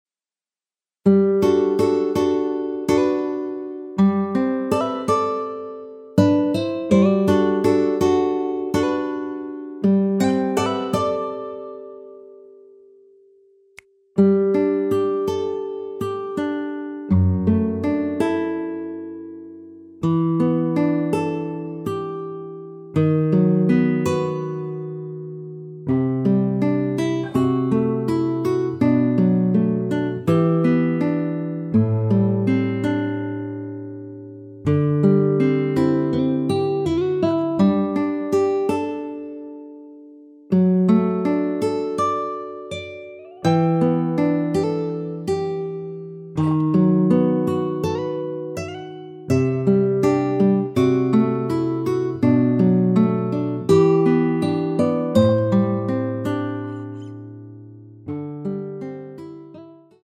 노래방에서 음정올림 내림 누른 숫자와 같습니다.
앞부분30초, 뒷부분30초씩 편집해서 올려 드리고 있습니다.
중간에 음이 끈어지고 다시 나오는 이유는